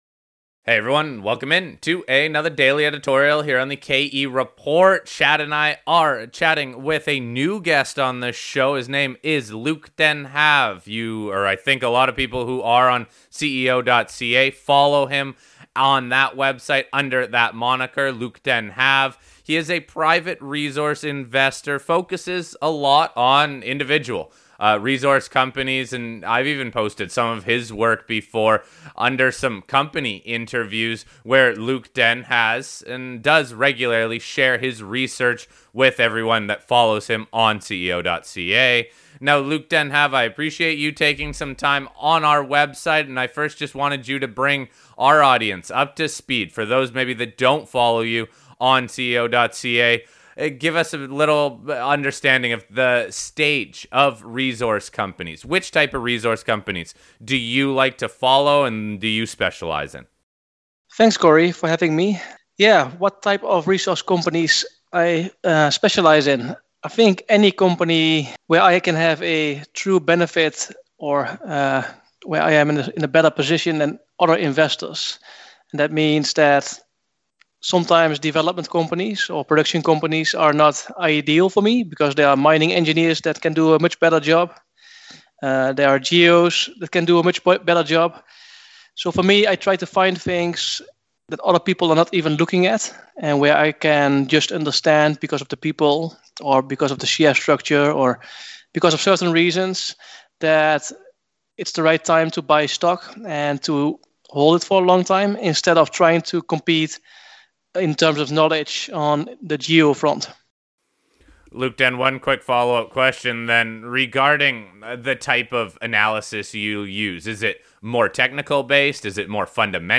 Stocks mentioned in the interview include: